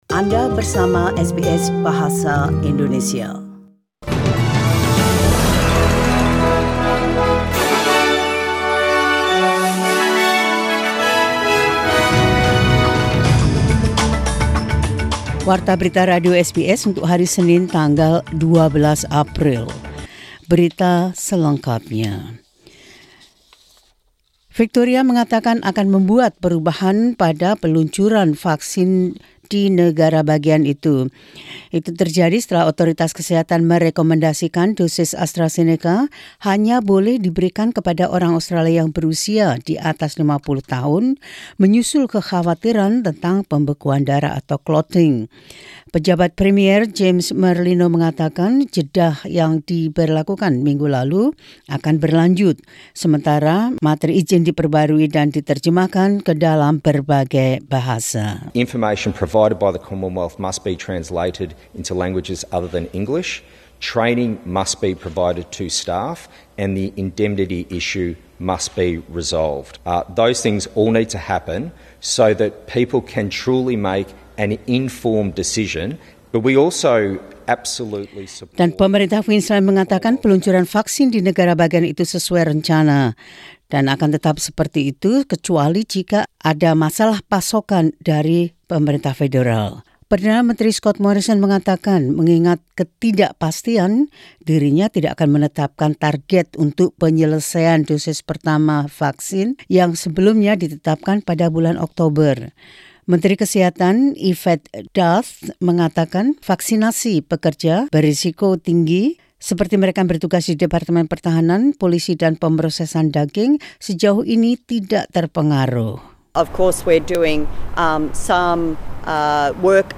SBS News Indonesian Program – 12 Apr 2021.